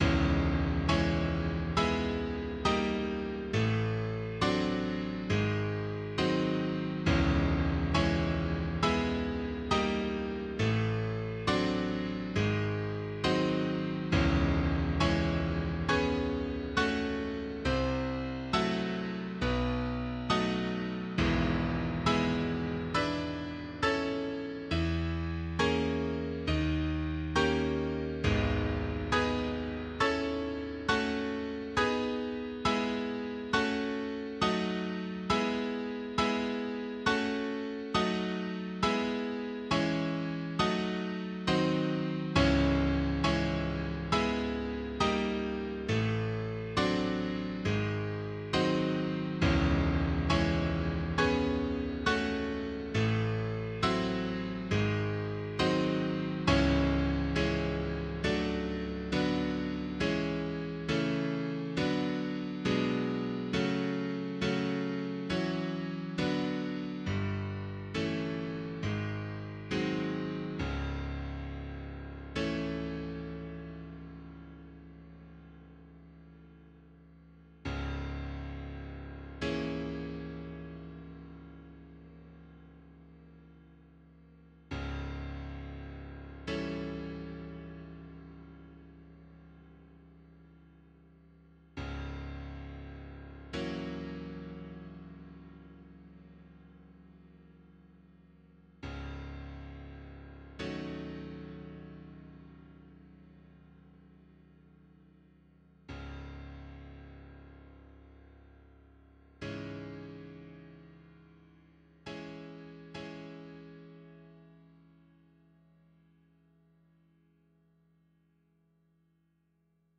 Enkele maten vanaf maat 46 van de Prelude in cis mineur, nr. 2, uit Morceaux de fantaisie, op. 3, van Rachmaninov. Opgemaakt via de MediaWiki-ondersteuning van LilyPond, zodat er ook uitvoer voor een synthesizer is.[noot 1]